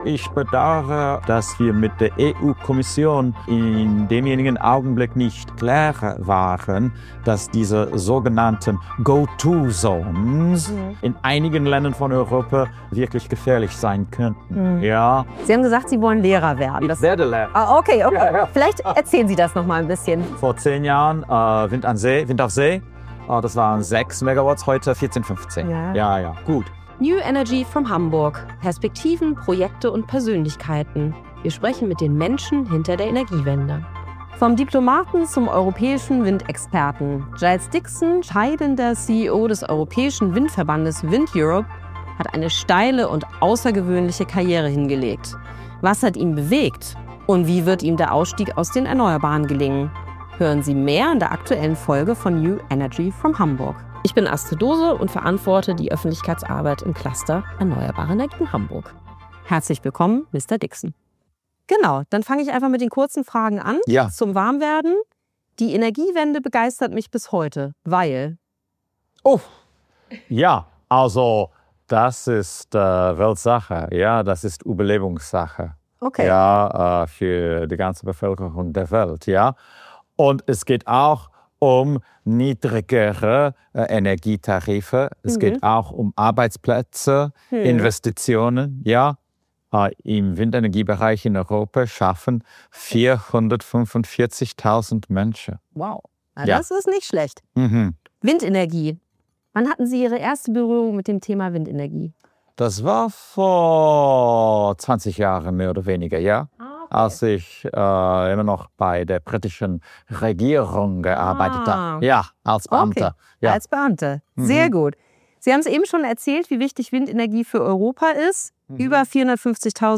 Windenergie in Europa: Rückblick, Fortschritt und Zukunft - Interview